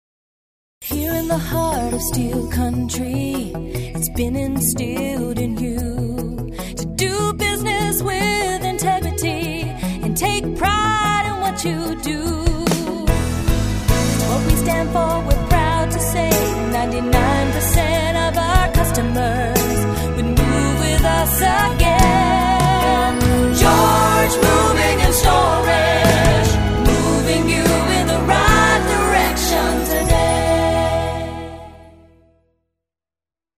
Tags: Jingle Music Marketing Musical Image Branding